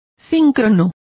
Complete with pronunciation of the translation of synchronous.